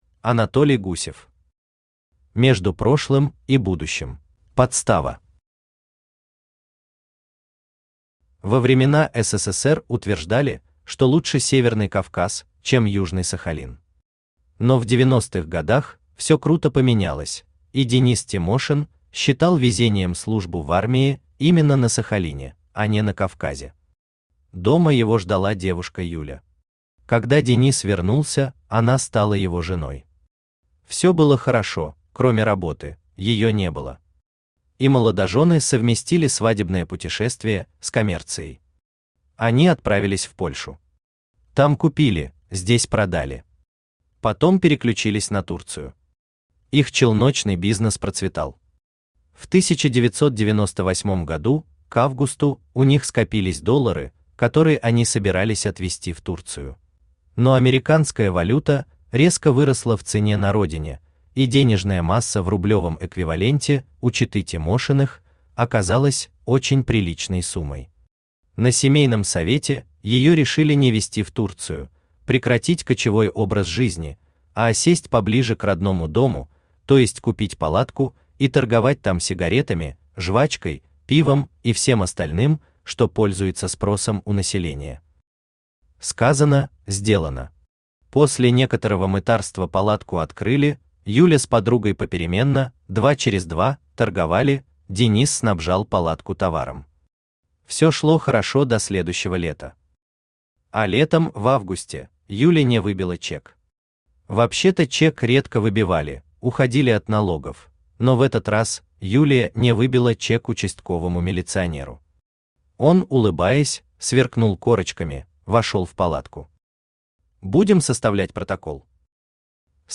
Аудиокнига Между прошлым и будущим | Библиотека аудиокниг
Aудиокнига Между прошлым и будущим Автор Анатолий Алексеевич Гусев Читает аудиокнигу Авточтец ЛитРес.